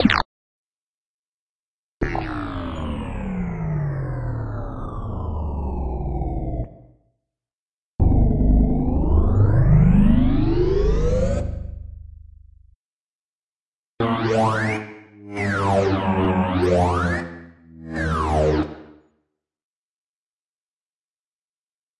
游戏 " 外星人引擎
描述：未来派的引擎
Tag: 科幻 太空飞船 发动机